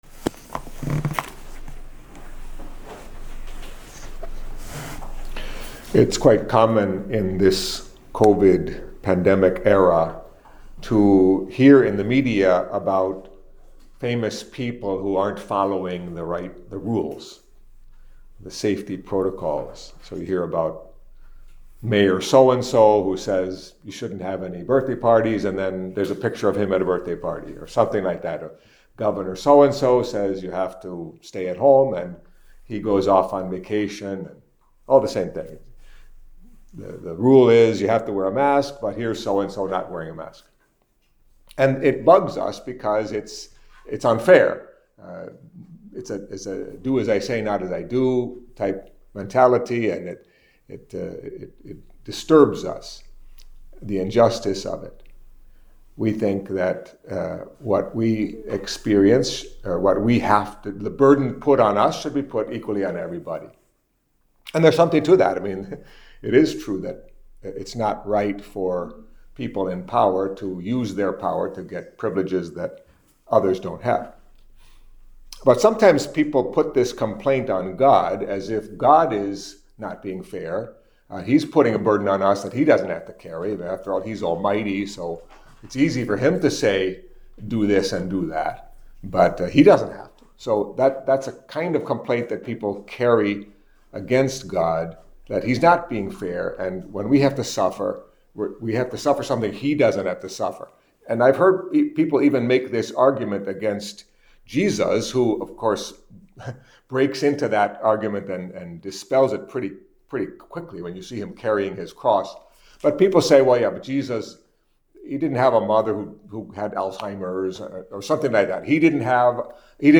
Catholic Mass homily for Tuesday of the 29th Week in Ordinary Time